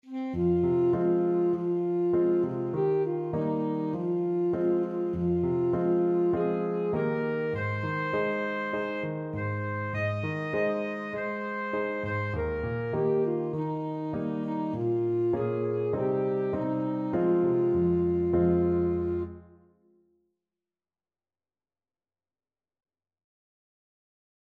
Alto Saxophone
2/4 (View more 2/4 Music)
Moderato